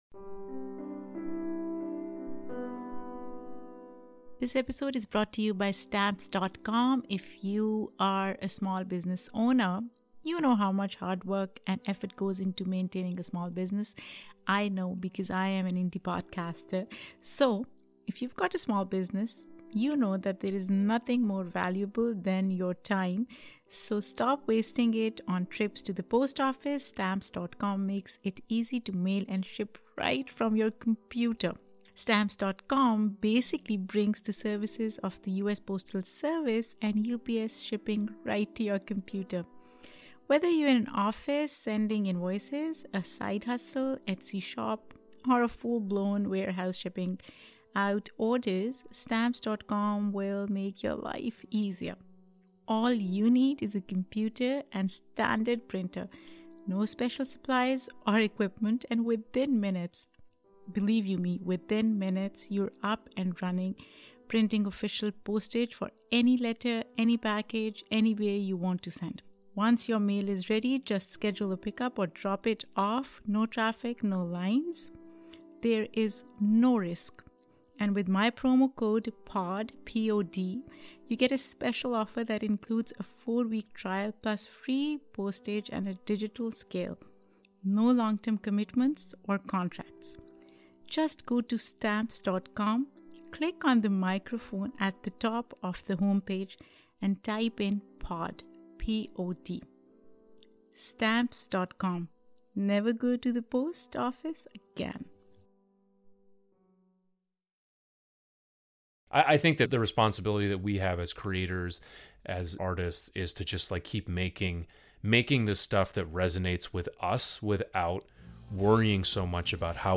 Rabab Music